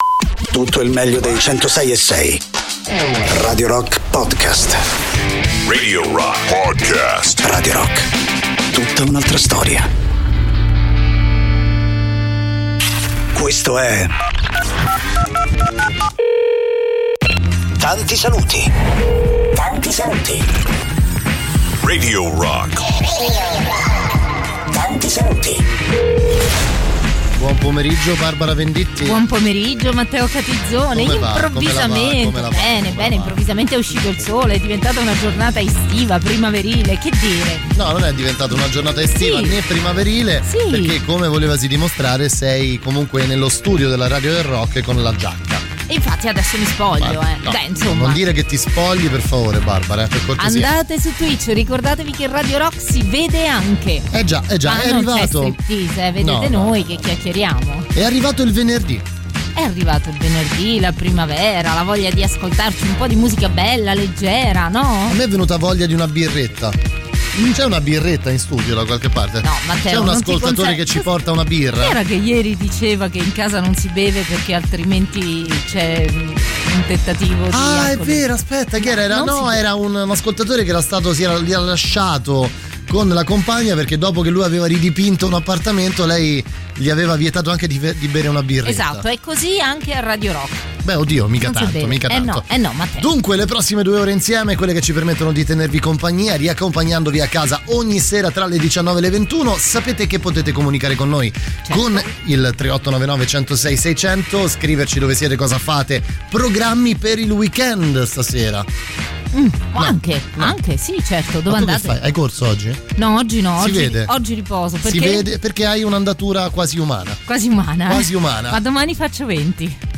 in diretta dal lunedì al venerdì, dalle 19 alle 21, con “Tanti Saluti” sui 106.6 di Radio Rock.